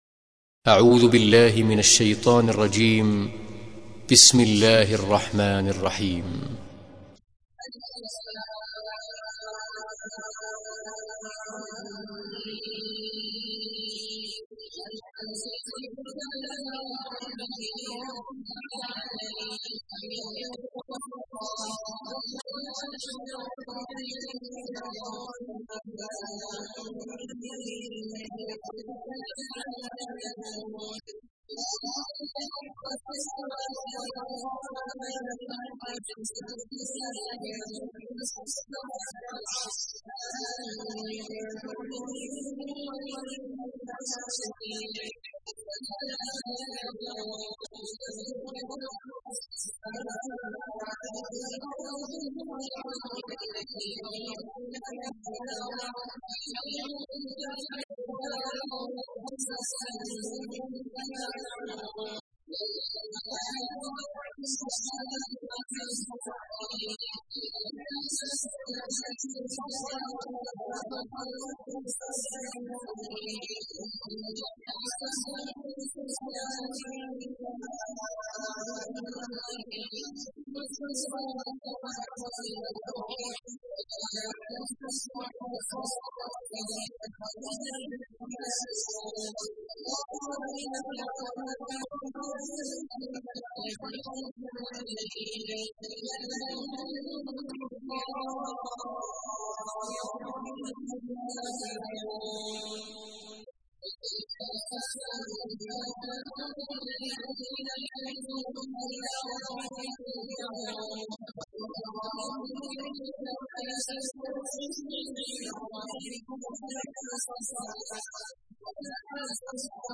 تحميل : 32. سورة السجدة / القارئ عبد الله عواد الجهني / القرآن الكريم / موقع يا حسين